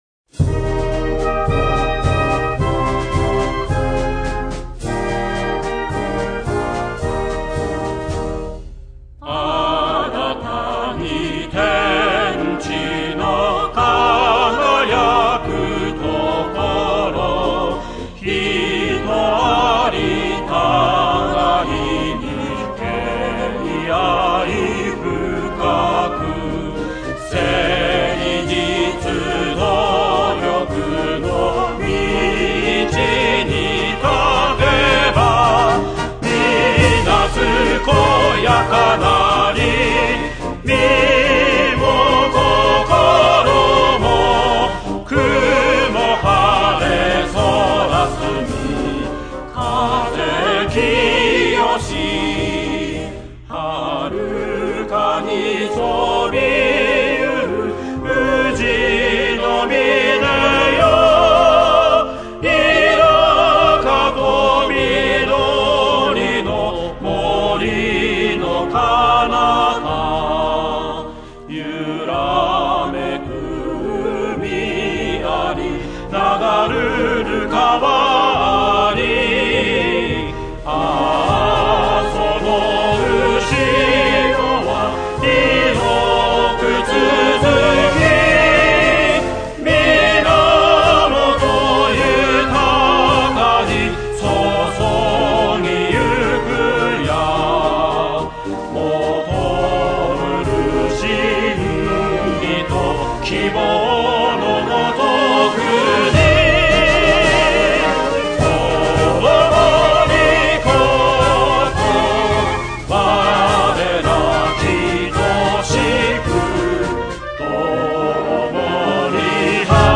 校歌演奏